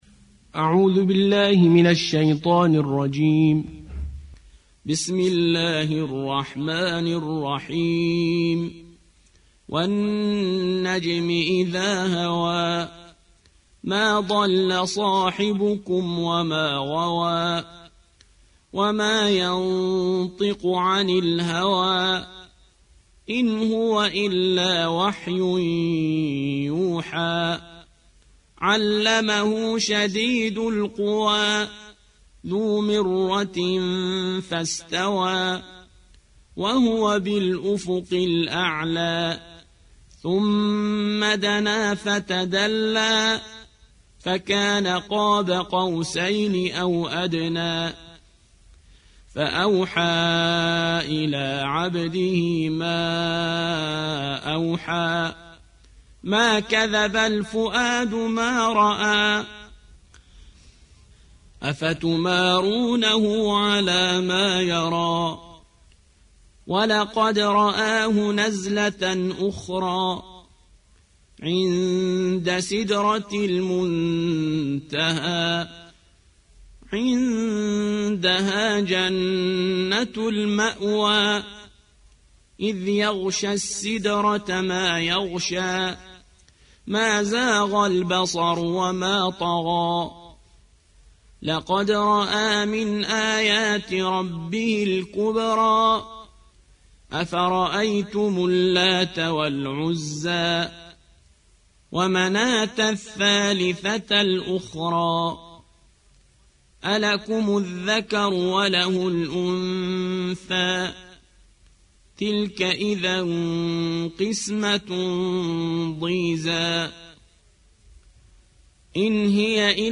سورة النجم / القارئ